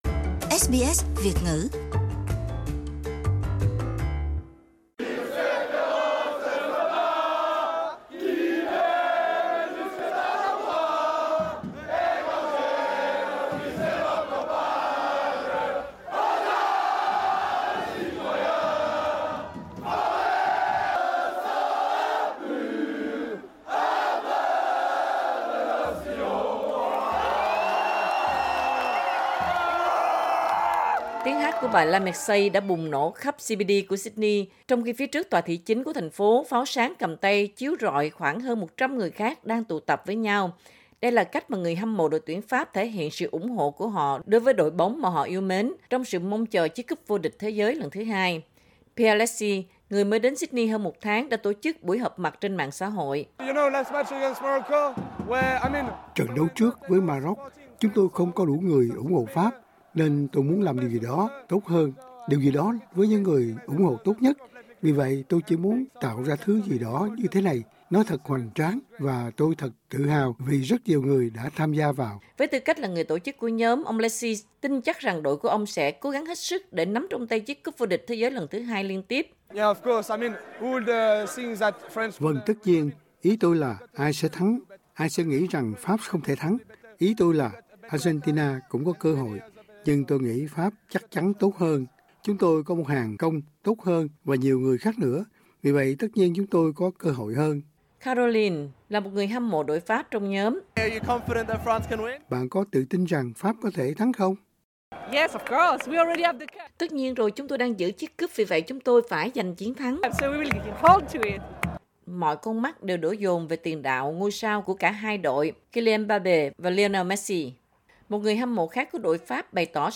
Các quán bar và một số khu vực quanh Sydney là nơi để các tín đồ túc cầu đã đổ xô đến để xem trận chung kết giữa Pháp và Argentina diễn ra đầu giờ sáng ngày thứ Hai. Không khí trận chung kết căng thẳng bao niêu thì không khí của người xem cũng hừng hực hào hứng không kém phần với những tiếng hô vang lúc thì Lionel Messi lúc thì Kylian Mbappe, và cuối cùng thì chỉ còn lại tiếng hô Lionel Messi, người hùng của trận chung kết.